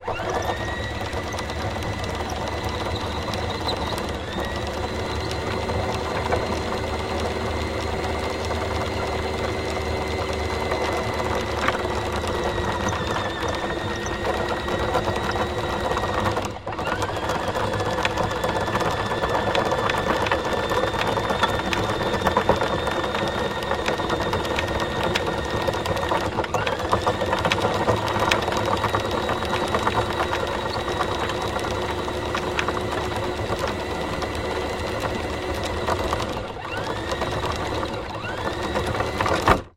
Звуки якоря
Звук автоматического механизма для подъема и опускания якоря